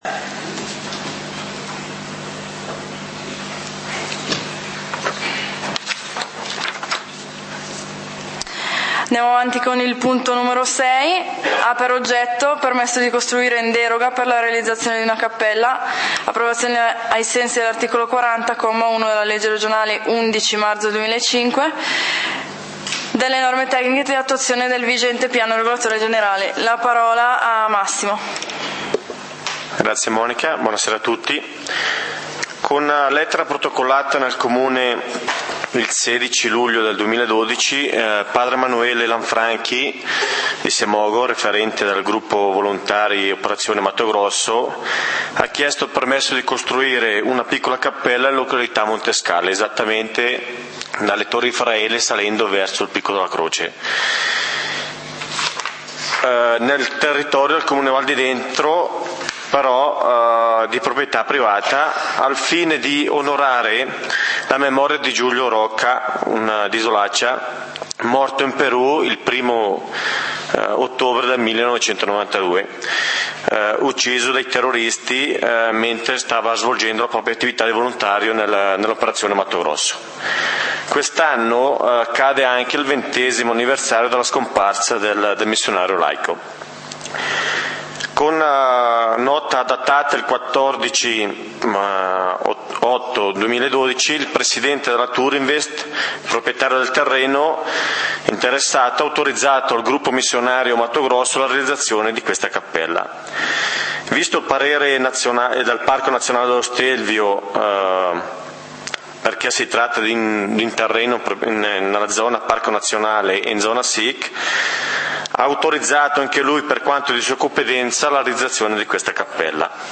Consiglio comunale del 27 Settembre 2012